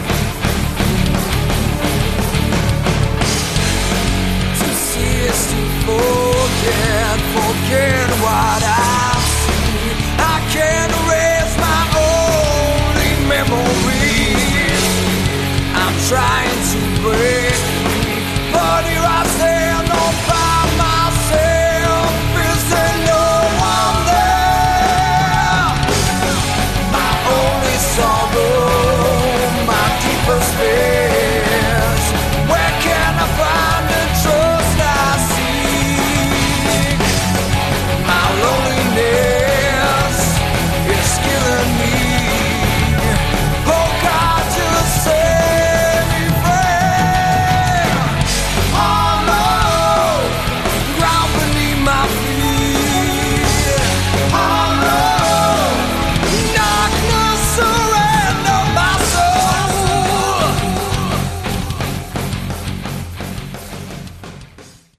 Category: Melodic Prog Rock
electric and acoustic guitars
keyboards, backing vocals
drums, backing vocals